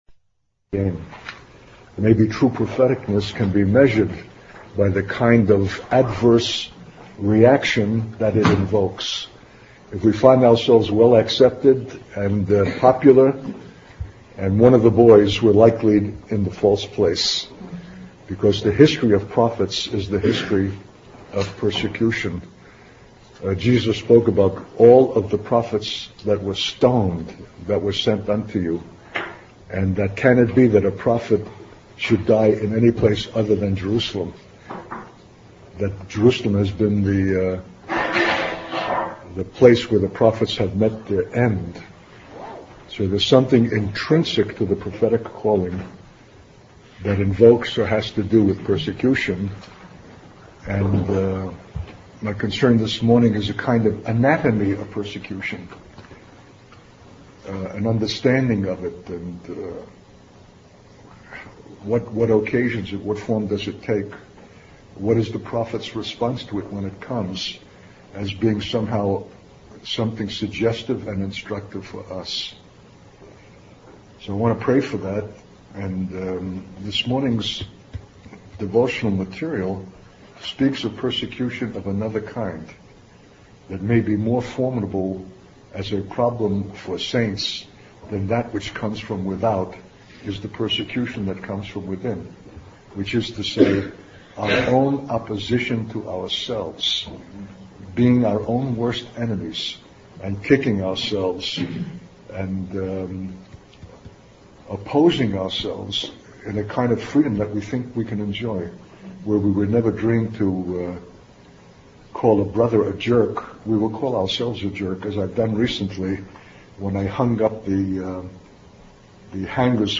In this sermon, the speaker discusses the significance of the last days for Israel and their eternal indebtedness to God's deliverance.